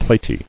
[plat·y]